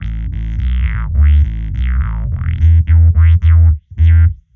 Index of /musicradar/dub-designer-samples/105bpm/Bass
DD_JBassFX_105A.wav